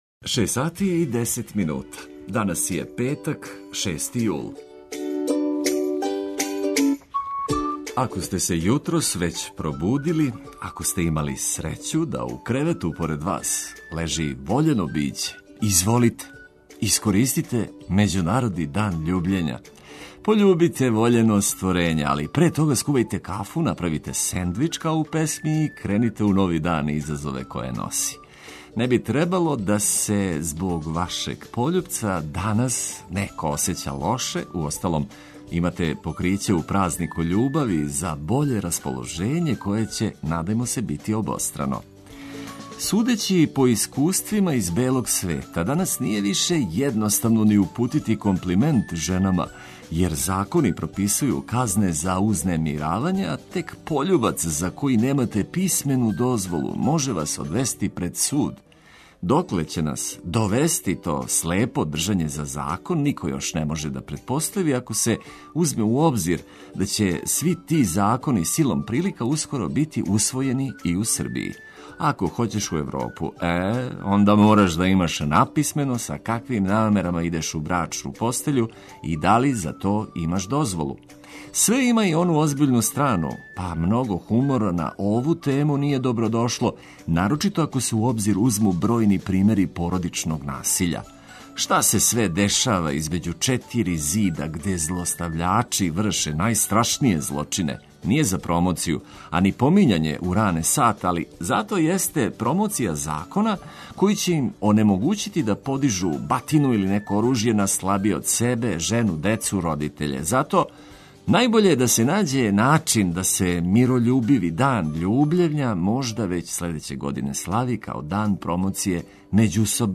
Уз хитове за сва времена и важне информације дочекајте нови дан у нашем друштву.